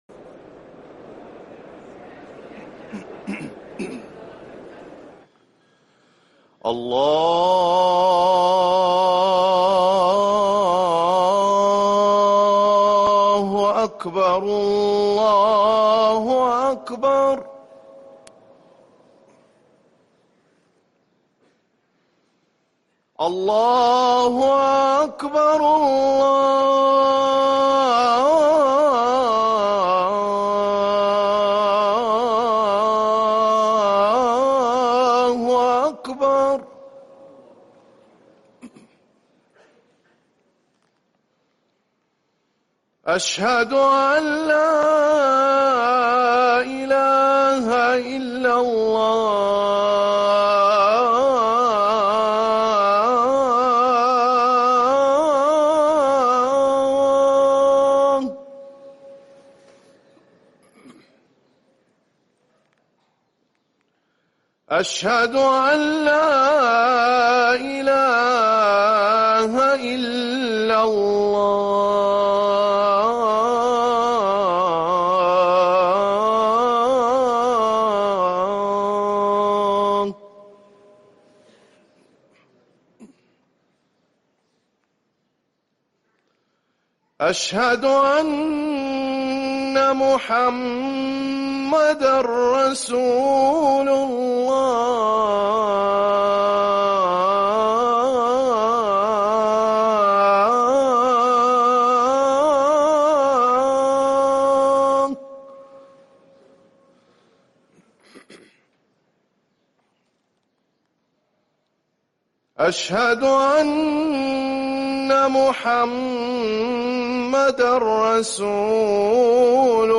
اذان الظهر
ركن الأذان